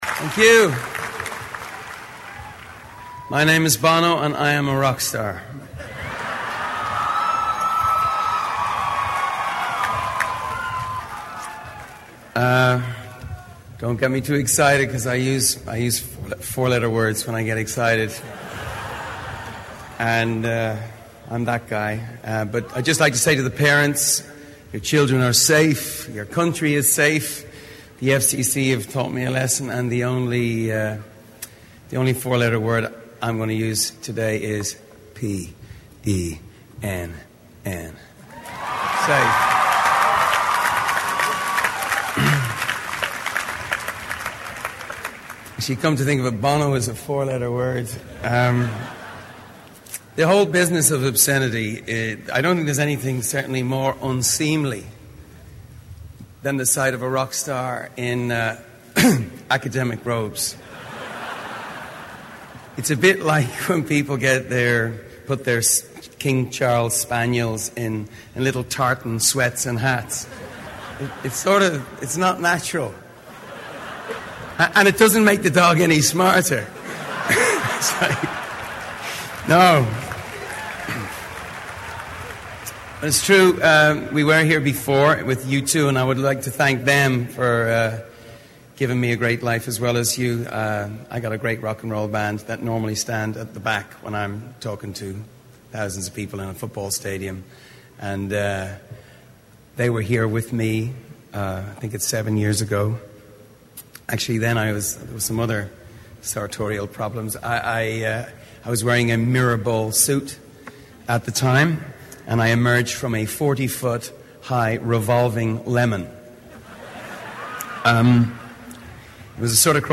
在线英语听力室名人励志英语演讲 第155期:奏出生命中最美的乐曲(1)的听力文件下载,《名人励志英语演讲》收录了19篇英语演讲，演讲者来自政治、经济、文化等各个领域，分别为国家领袖、政治人物、商界精英、作家记者和娱乐名人，内容附带音频和中英双语字幕。